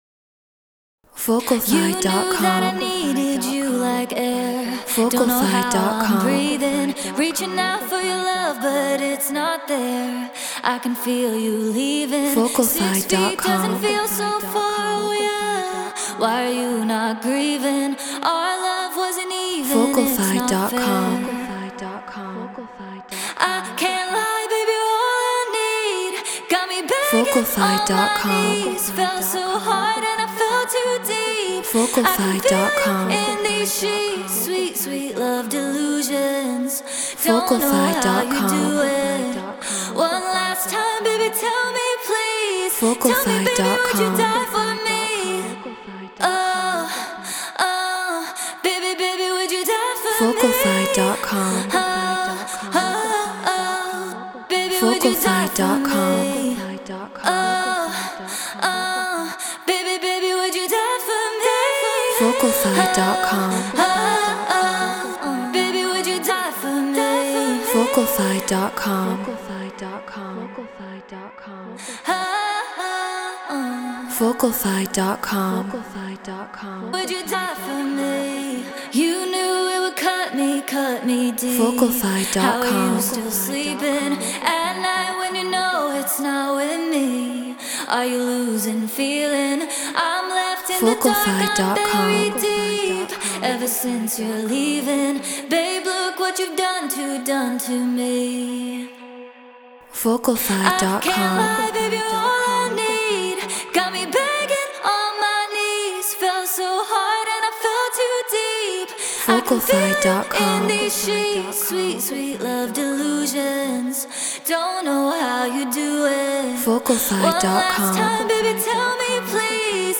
Drum & Bass 174 BPM F#min
Treated Room